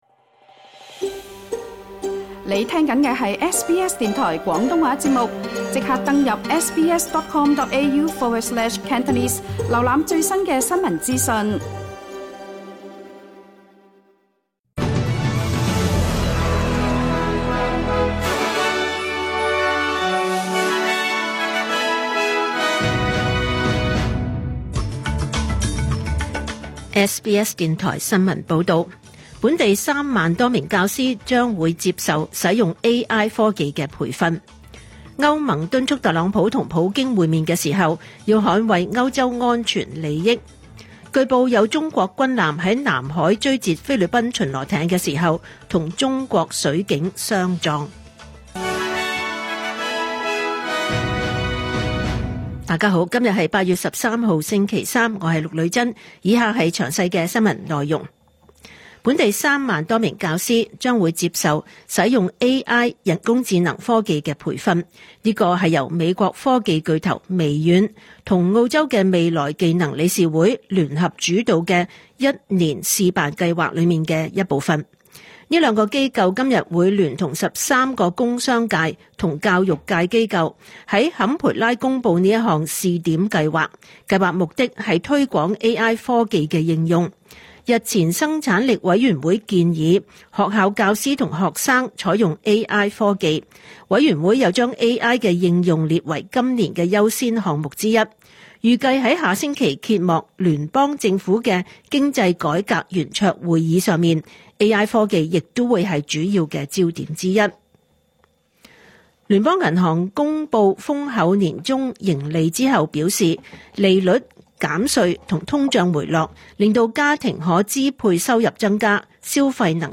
2025 年 8 月 13 日 SBS 廣東話節目詳盡早晨新聞報道。